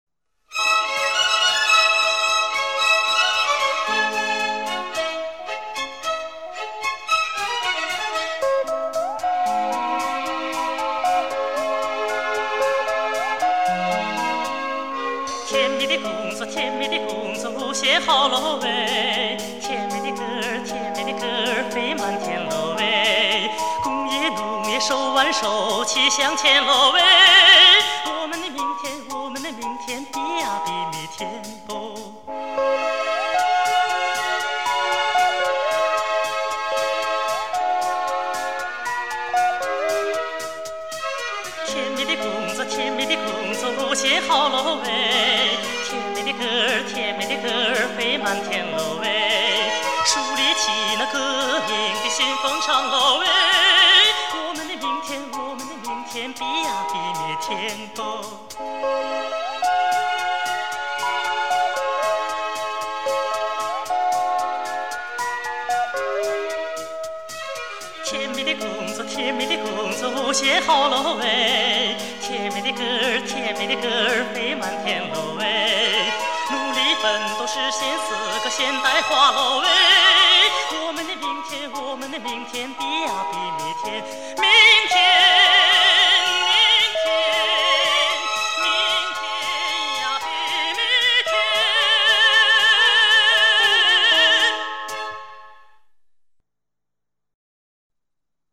怀旧影视插曲经典专辑